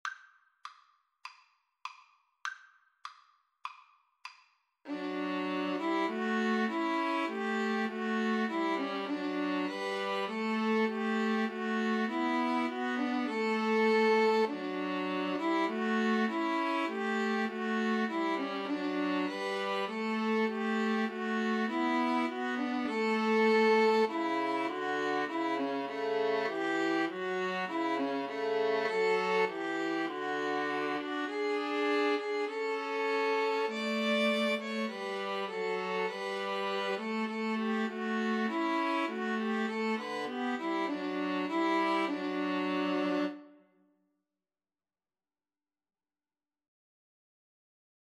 4/4 (View more 4/4 Music)
Classical (View more Classical String trio Music)